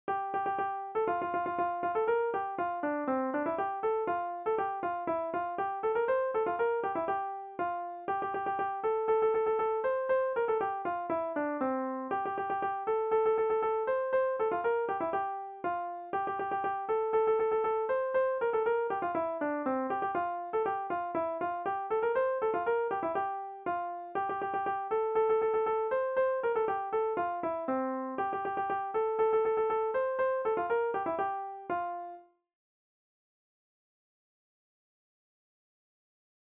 Saint-Philbert-du-Pont-Charrault
danse : pas d'été